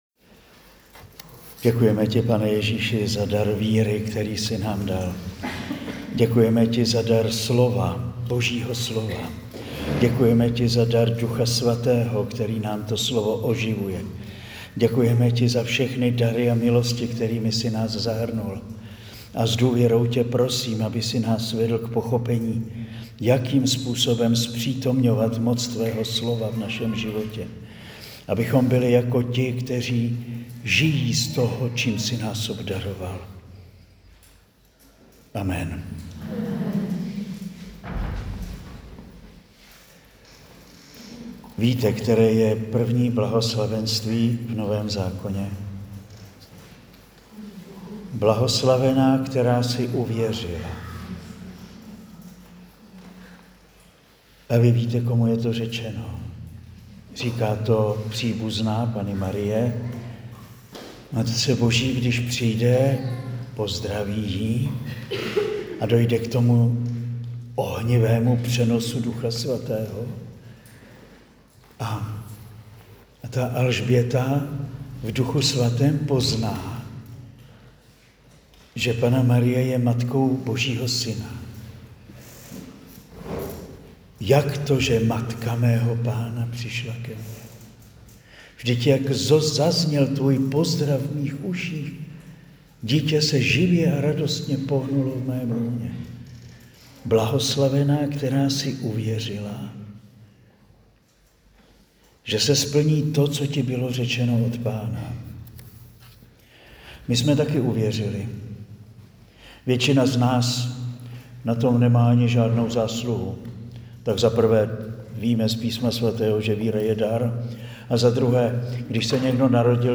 Přednáška zazněla na lednové duchovní obnově pro ženy v Želivi (2025).